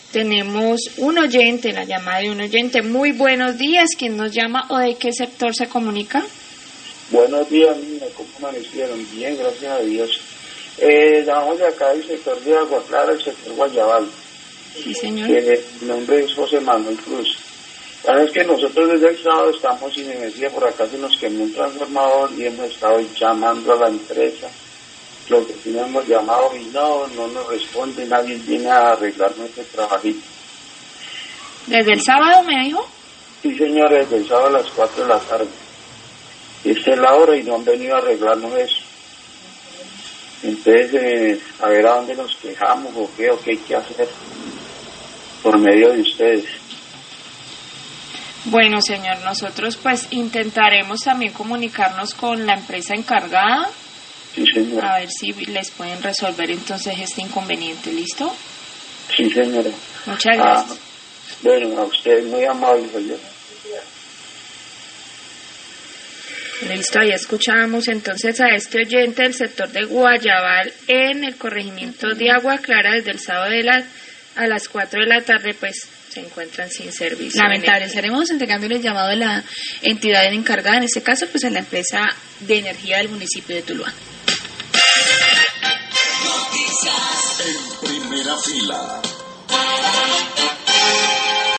Radio
queja oyentes